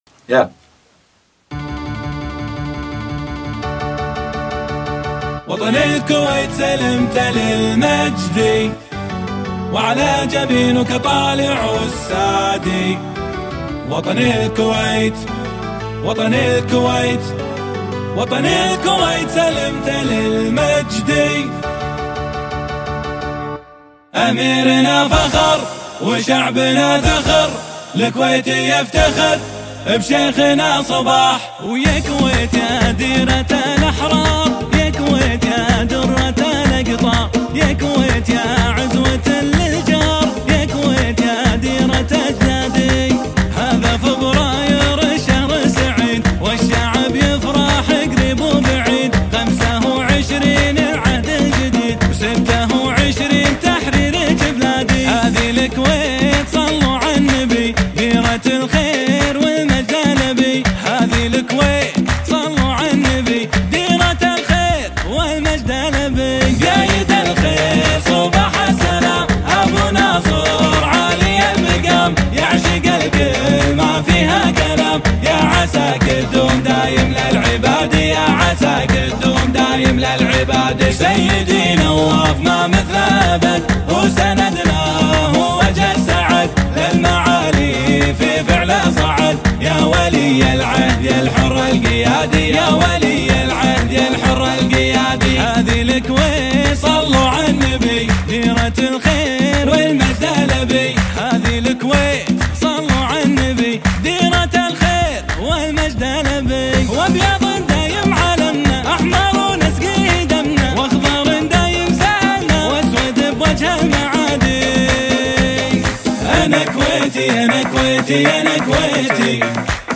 شيلة (وطنية)